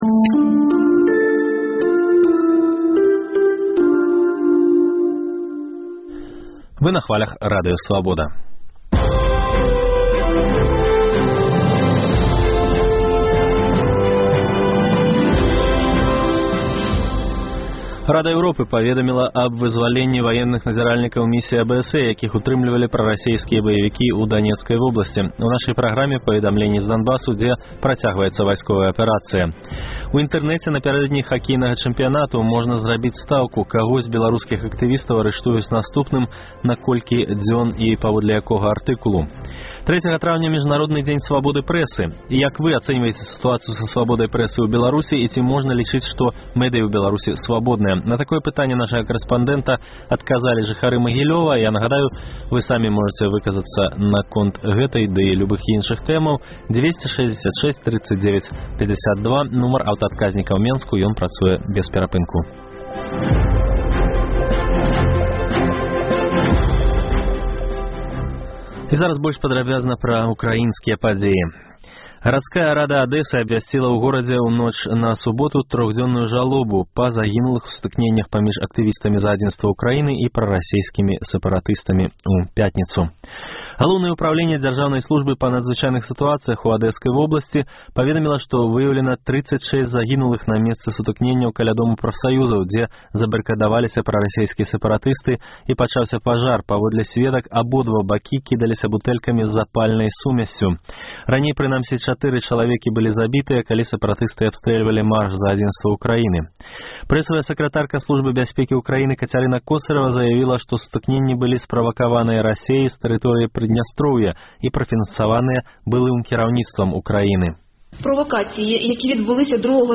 На такое пытаньне карэспандэнта «Свабоды» адказвалі жыхары Магілёва.